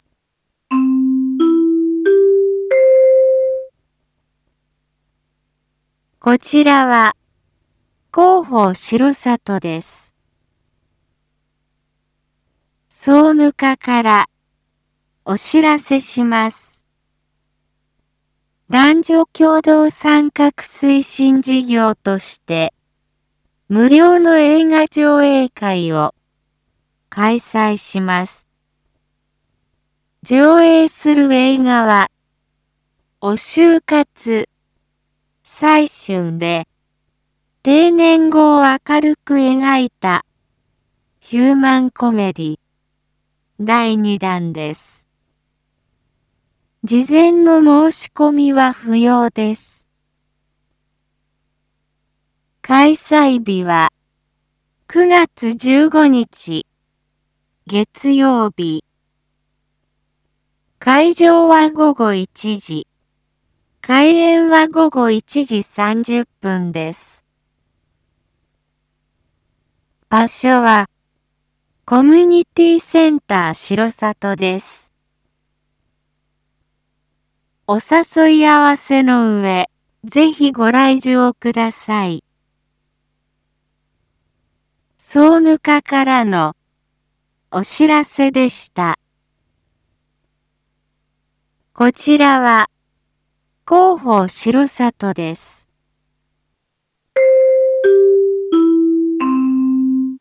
一般放送情報
Back Home 一般放送情報 音声放送 再生 一般放送情報 登録日時：2025-09-14 19:01:42 タイトル：男女共同参画推進事業 映画上映会② インフォメーション：こちらは、広報しろさとです。